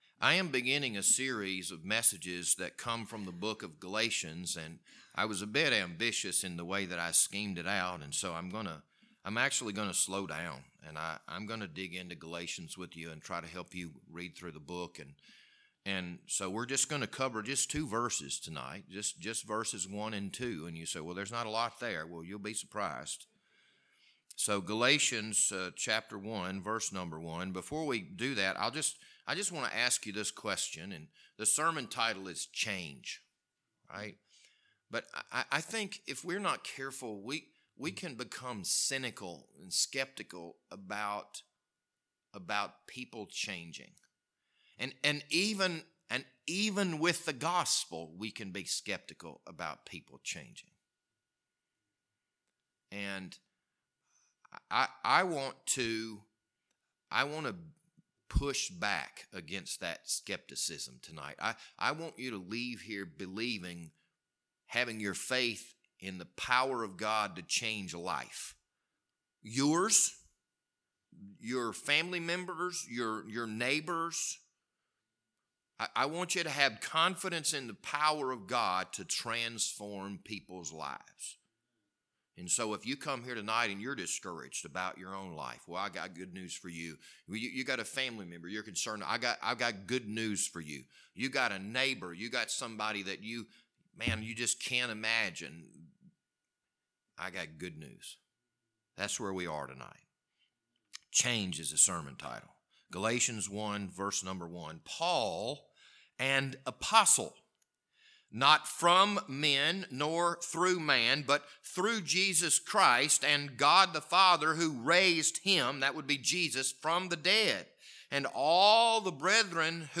This Sunday evening sermon was recorded on February 27th, 2022.